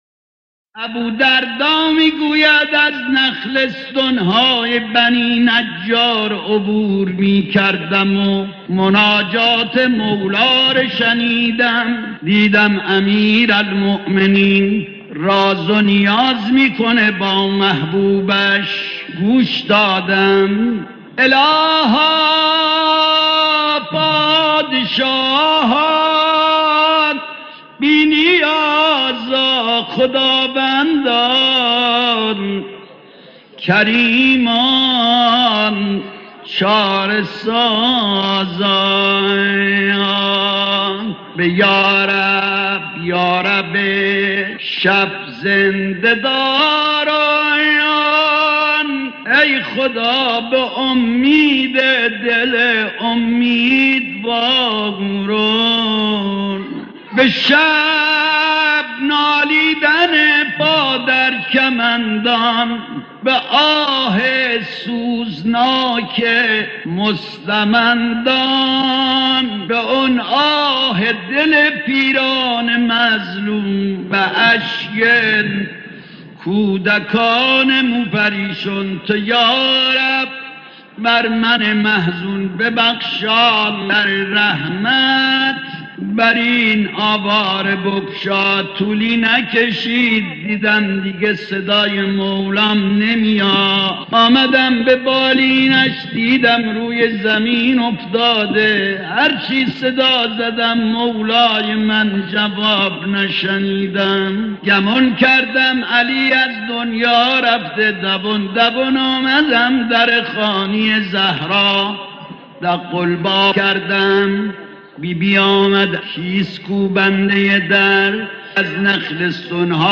مداحی قدیمی